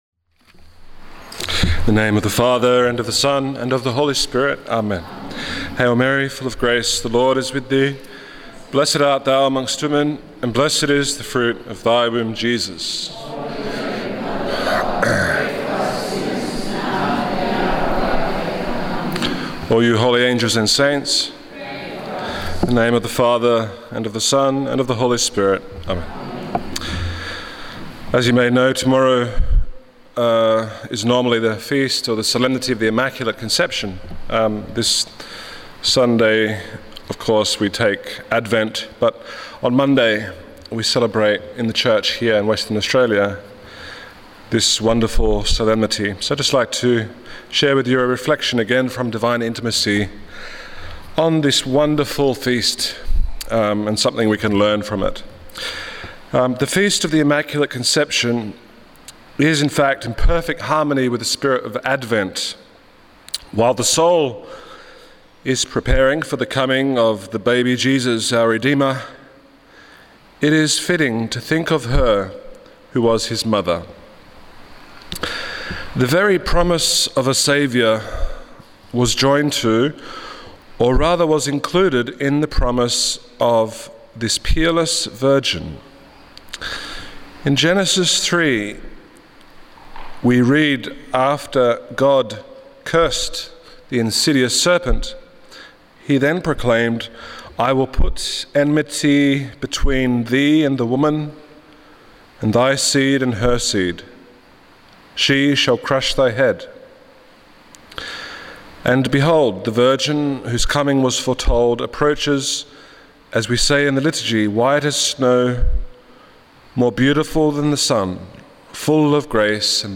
during the “Day With Mary” held at Corpus Christi Church in Mosman Park, Western Australia on 7 December 2013.